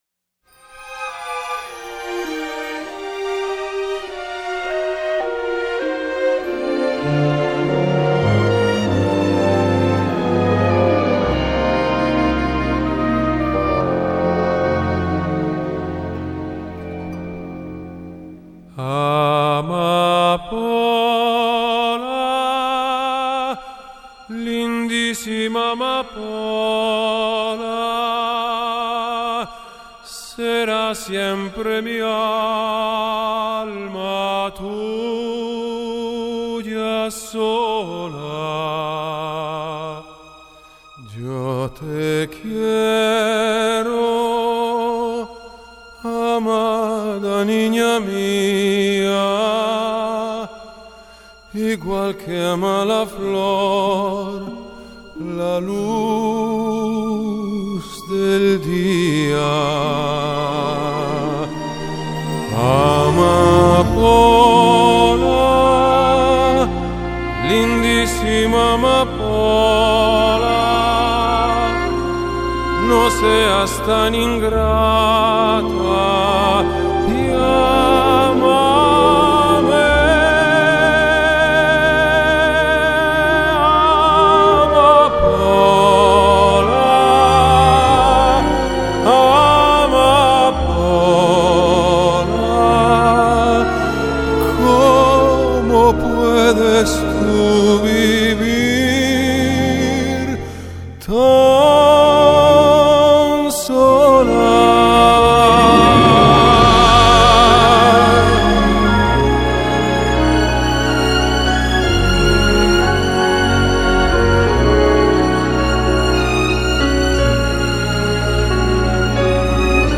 Pop, Classical Crossover